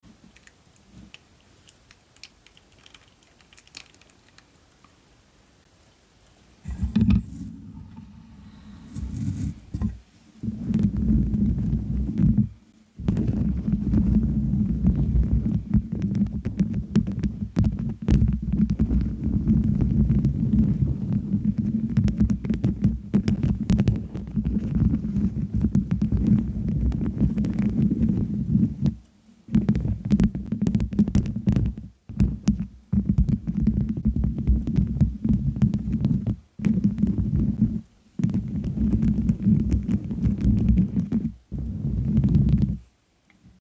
new teeth tapping method 👀😬😌🦷 sound effects free download